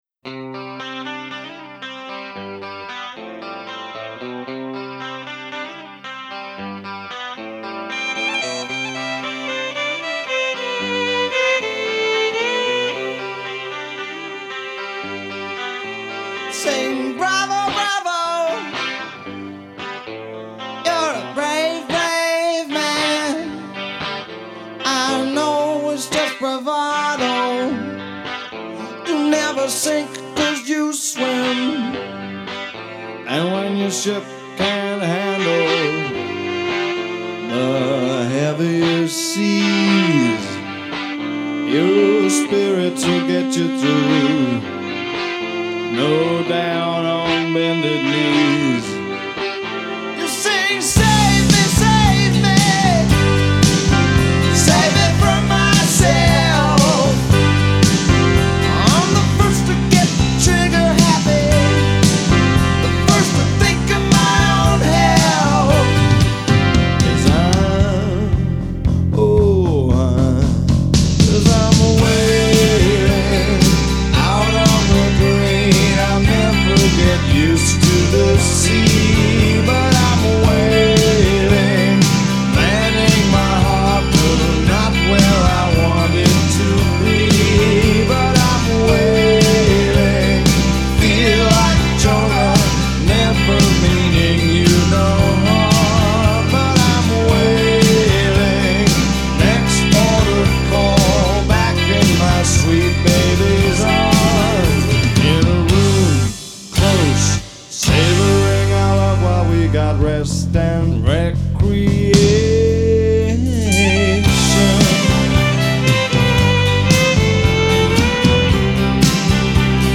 Genre: Hip Hop, Jazz, Rock, Funk